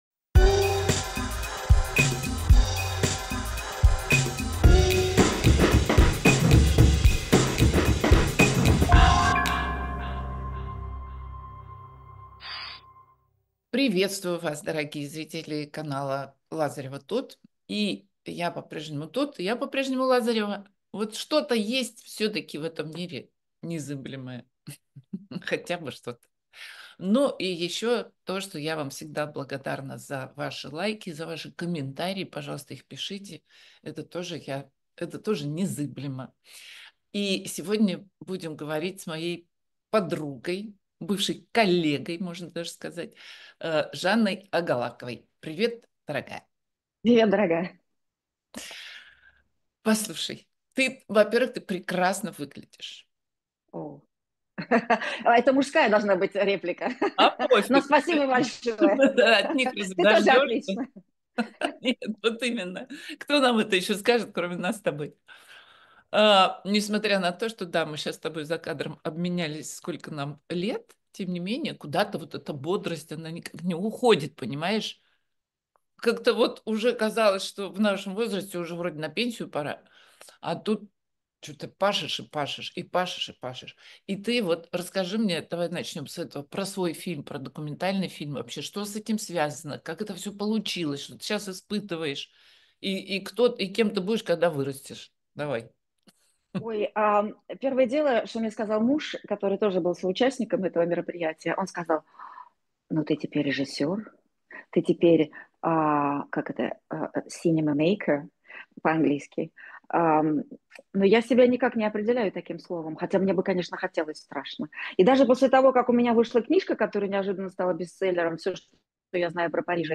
Эфир ведёт Татьяна Лазарева